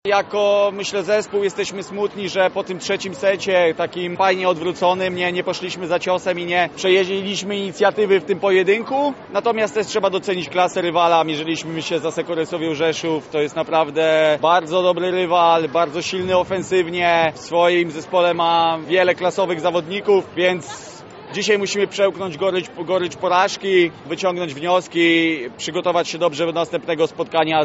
Wywiady